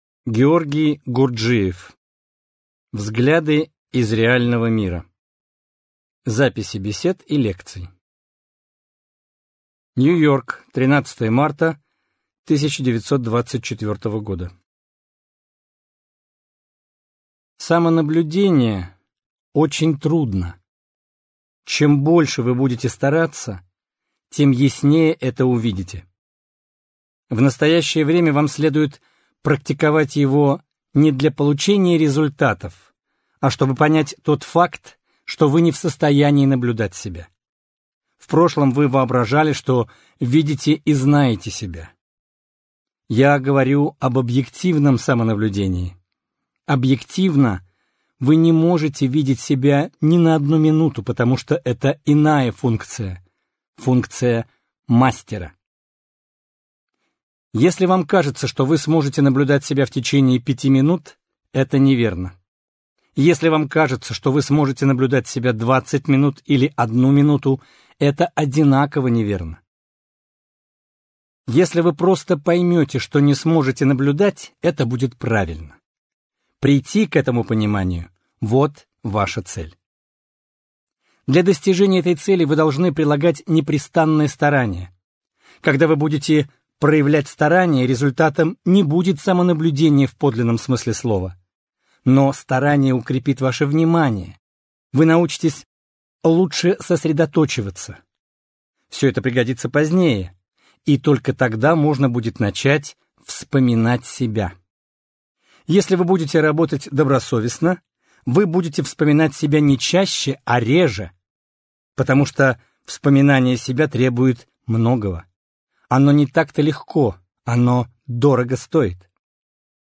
Аудиокнига Взгляды из реального мира | Библиотека аудиокниг